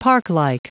Prononciation du mot parklike en anglais (fichier audio)
Prononciation du mot : parklike
parklike.wav